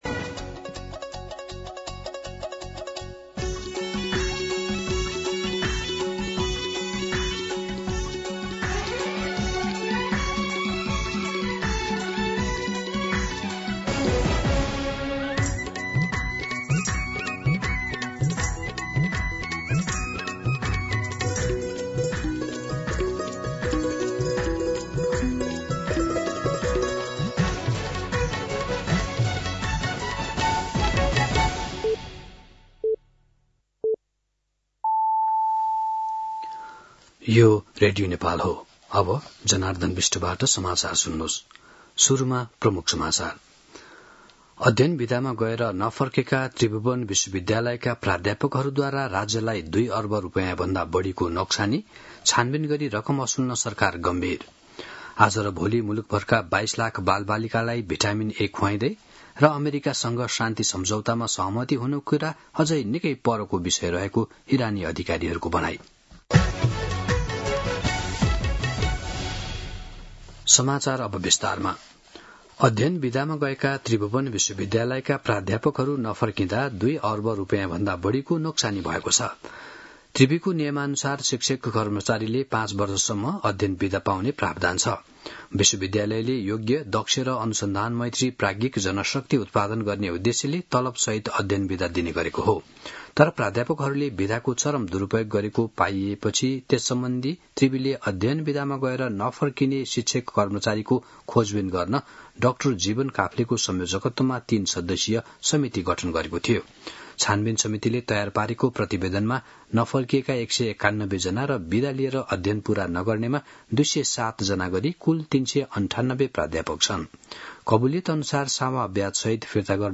दिउँसो ३ बजेको नेपाली समाचार : ६ वैशाख , २०८३